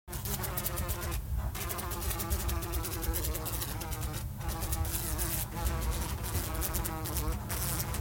دانلود صدای حشره 24 از ساعد نیوز با لینک مستقیم و کیفیت بالا
جلوه های صوتی